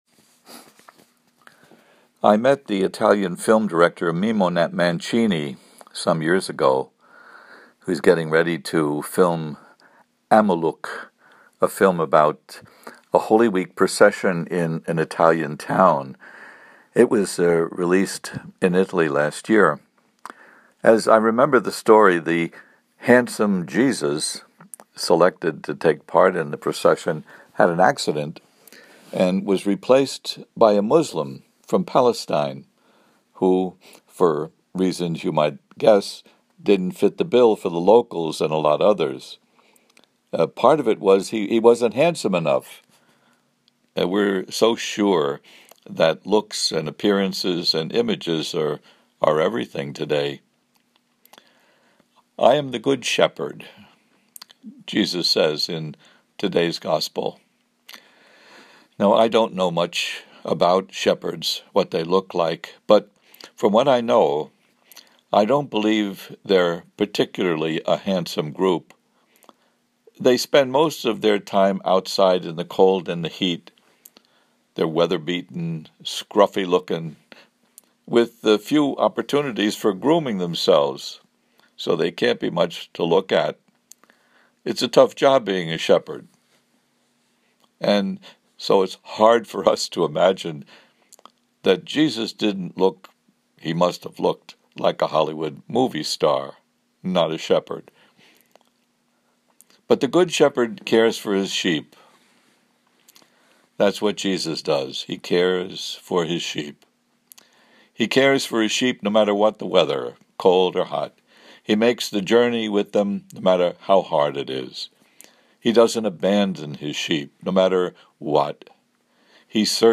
audio homily here: